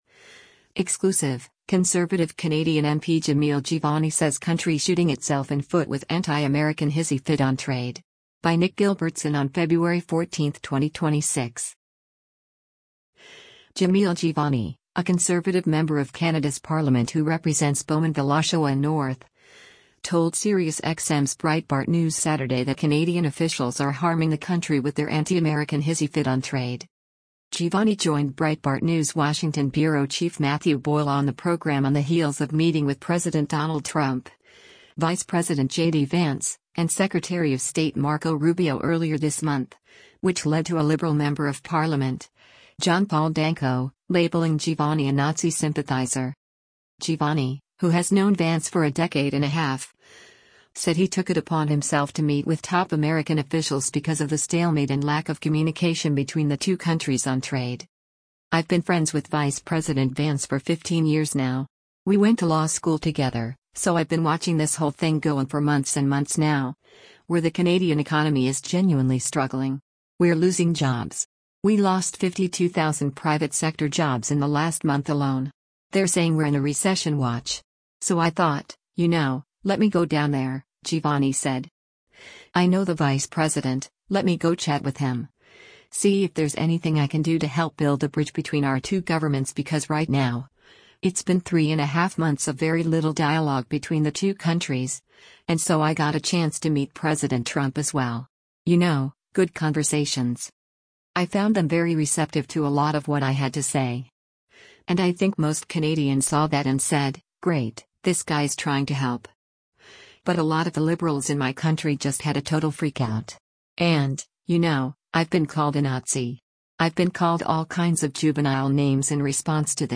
Jamil Jivani, a conservative member of Canada’s Parliament who represents Bowmanville-Oshawa North, told Sirius XM’s Breitbart News Saturday that Canadian officials are harming the country with their “anti-American hissy fit” on trade.